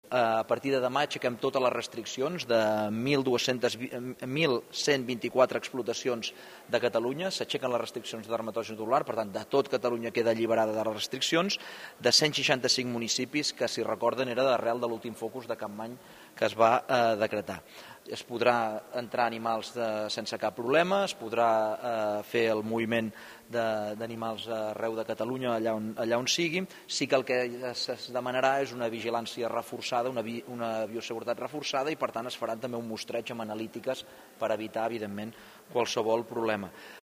En declaracions als mitjans des del Parlament, ha explicat que això allibera 1.124 explotacions i 165 municipis afectats per l’últim focus de Capmany (Alt Empordà).